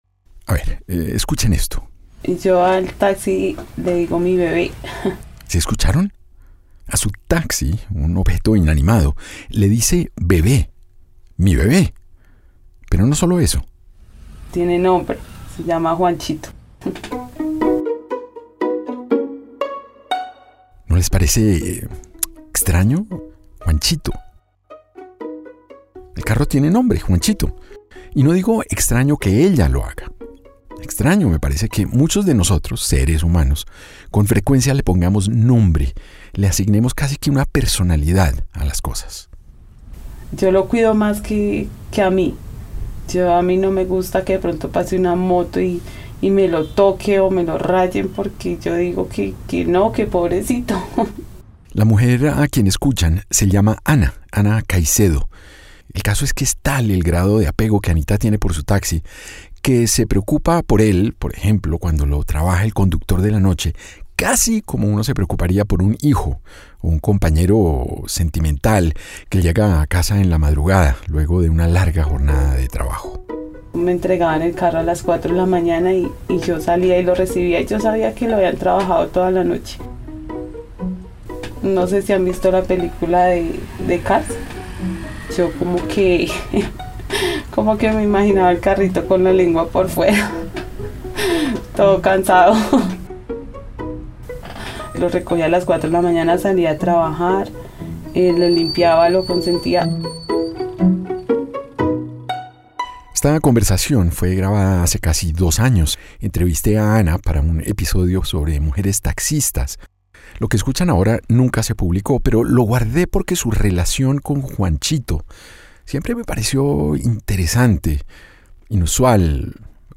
Descubre el poder del amor, o desamor, en este capítulo del pódcast narrativo Relatos Amarillos.
La vida es una travesía, una que insistimos hacer acompañados. En este capítulo escucharemos las historias de tres taxistas y una radio operadora que encuentran en el amor, o desamor, la manera más efectiva de conectar con los otros.